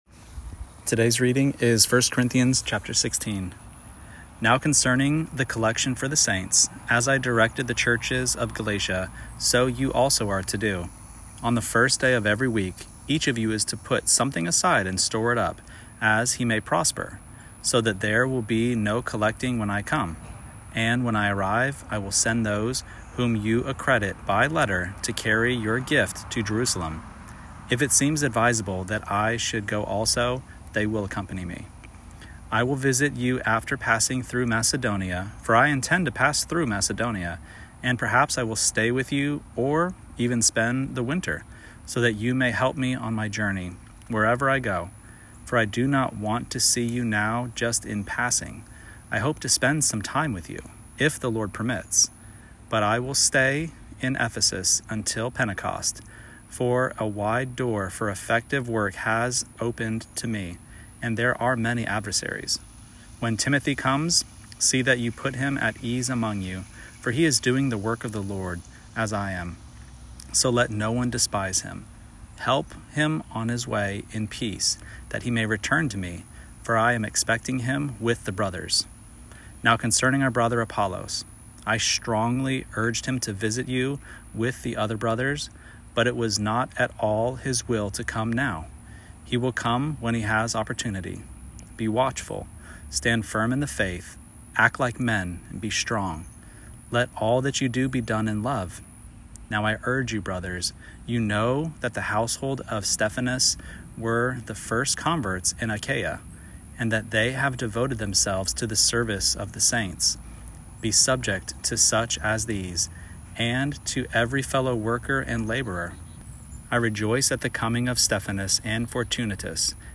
Daily Bible Reading (ESV)